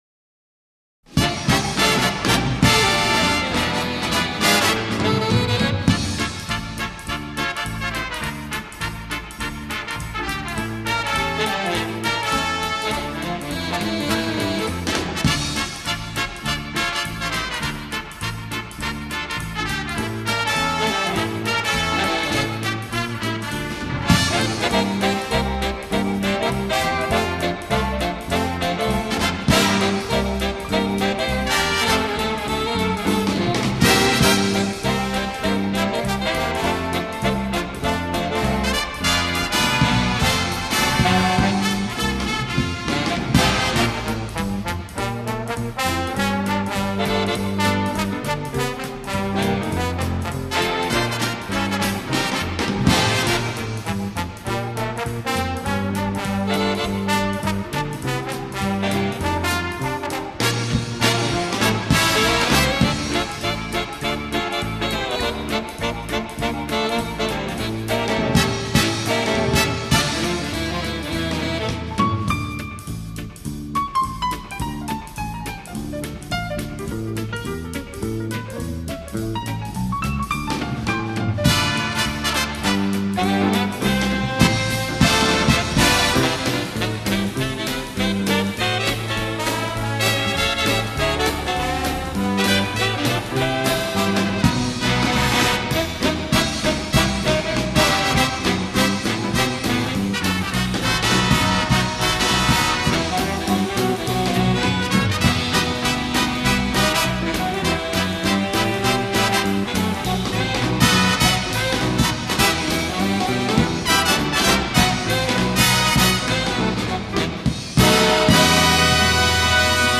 07 Quickstep